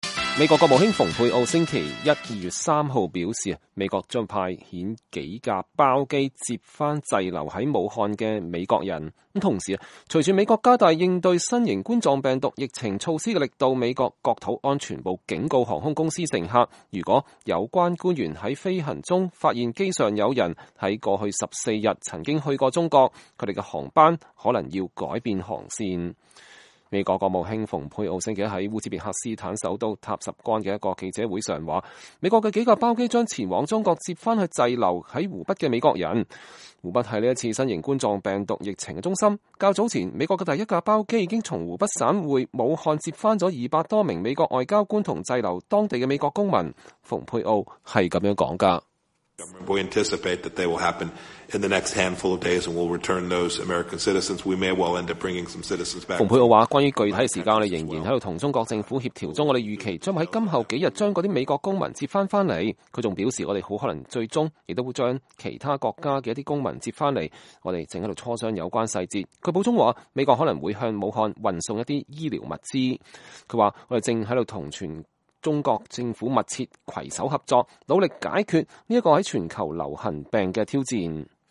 美國國務卿蓬佩奧在烏茲別克斯坦首都塔什幹舉行的記者會上講話。（2020年2月3日）